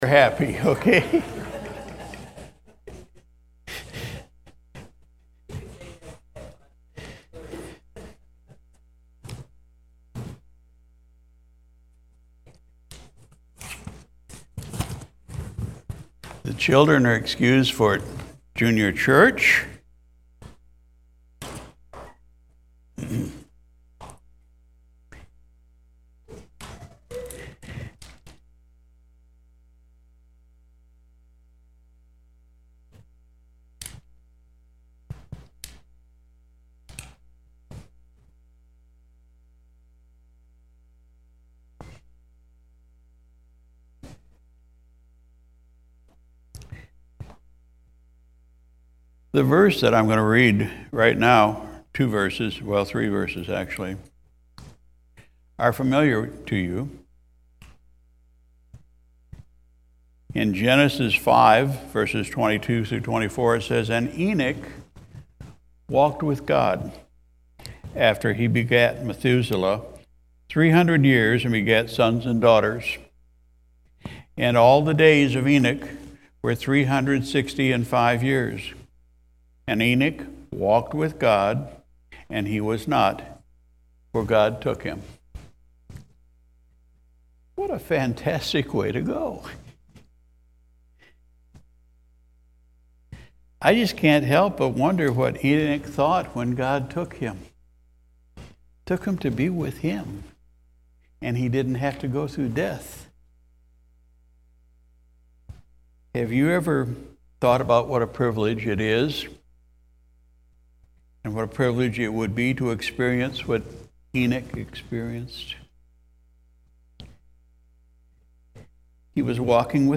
February 19, 2023 Sunday Morning Service Pastor’s Message: “Walking with God”